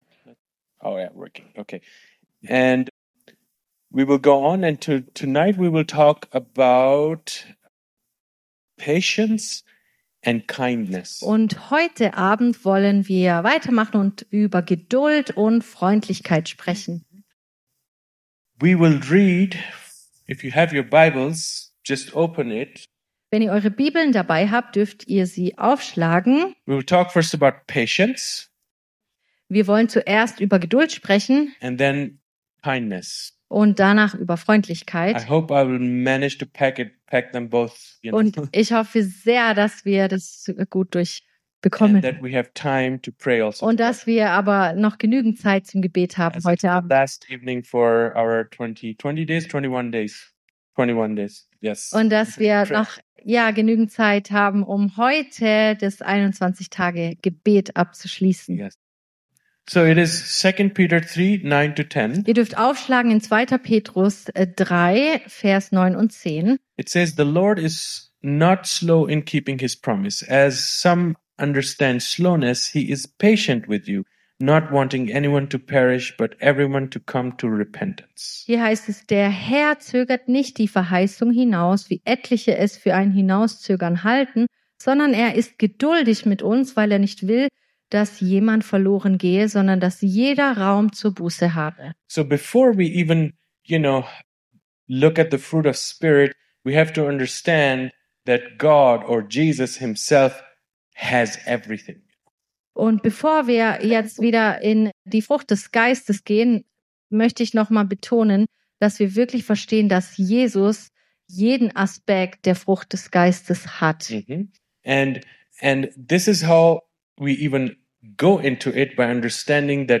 Vortrag
im Christlichen Zentrum Villingen-Schwenningen.